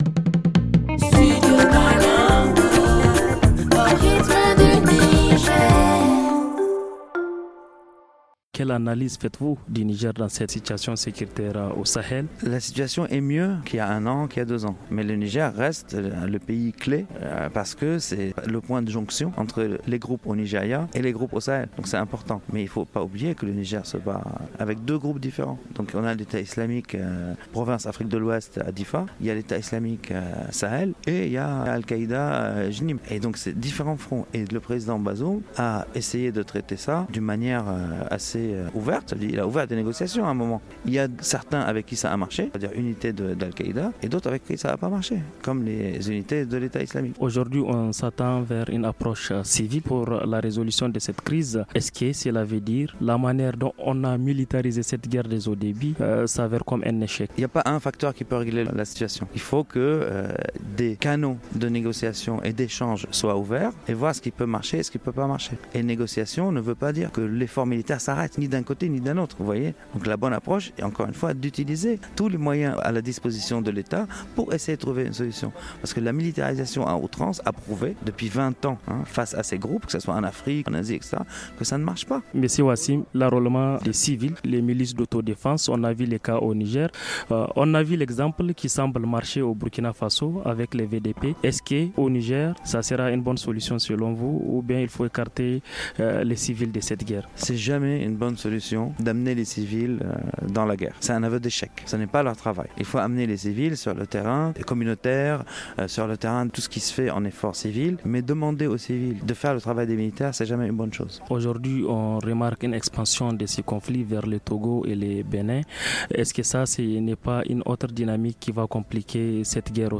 Interview sur la sécurité au Sahel